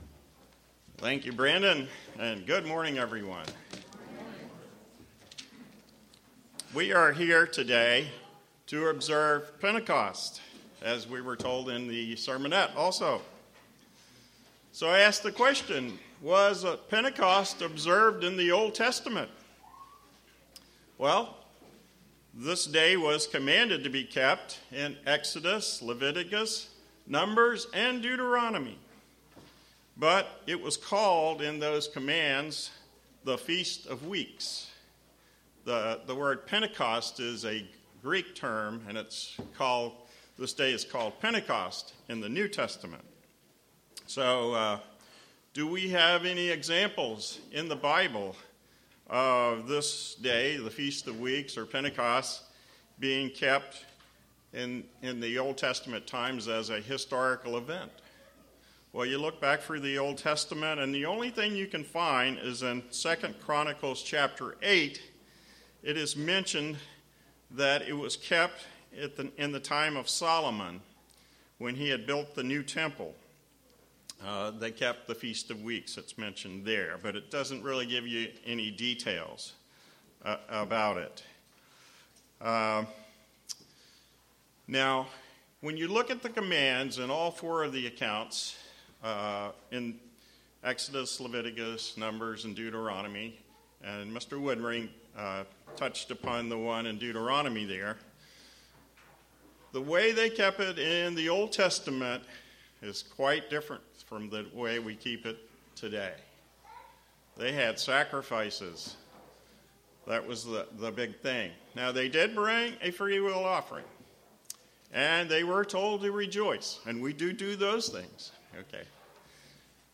The giving of the Ten Commandments at Mt Sinai was given around the time of Pentecost. This sermon looks at some of the similarities and differences of the Mt Sinai event and the Pentecost event of Acts 2.